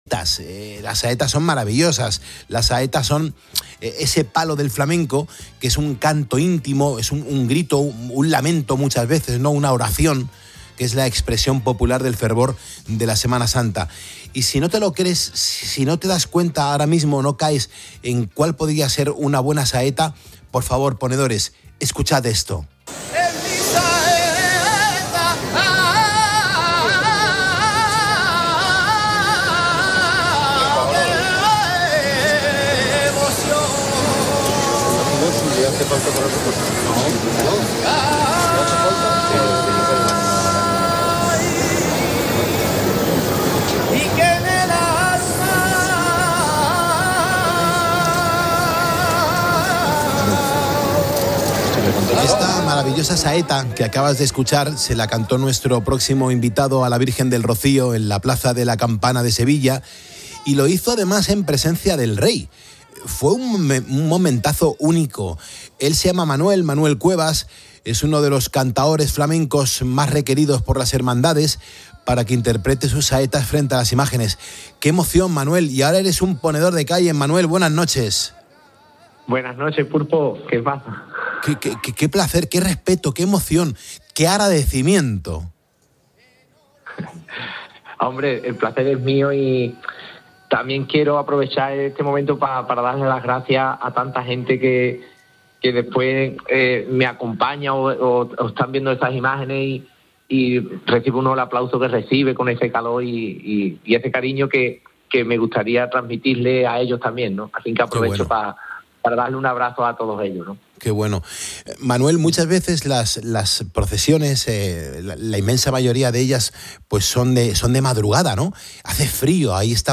En una entrevista en el programa Poniendo las Calles de COPE